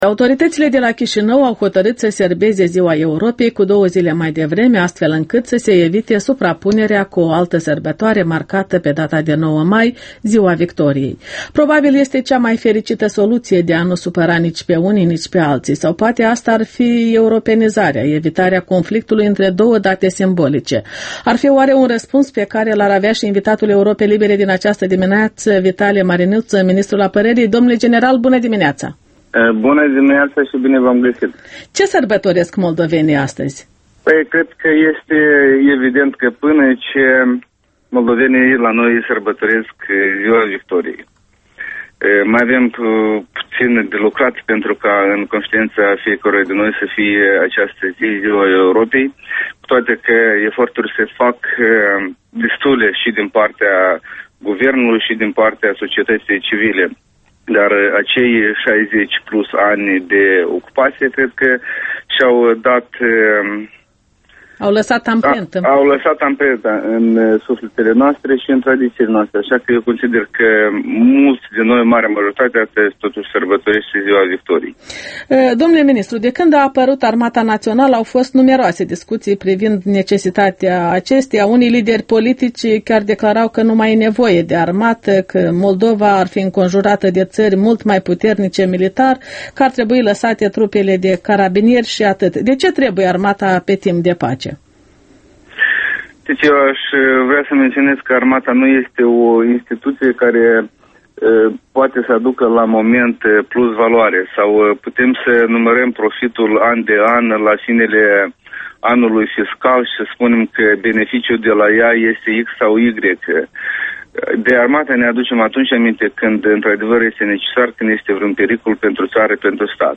Interviul matinal EL: cu Vitalie Marinuța, ministrul apărării